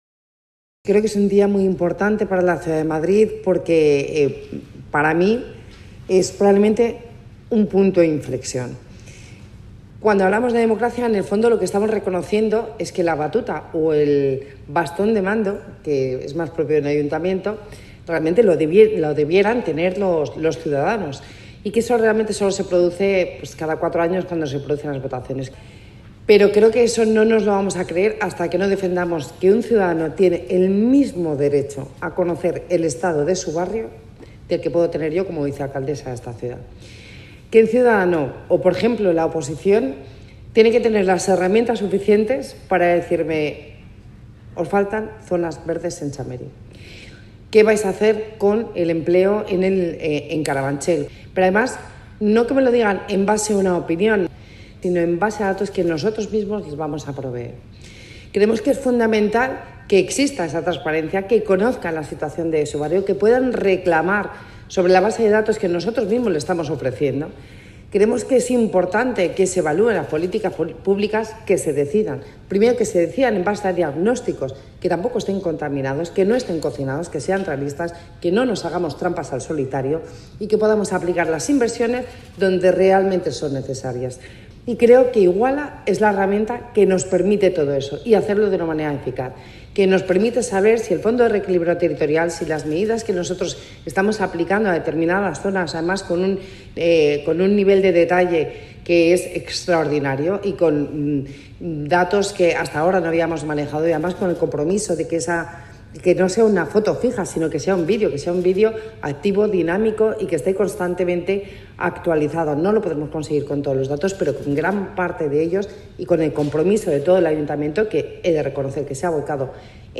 Nueva ventana:Declaraciones de la vicealcaldesa, Begoña Villacís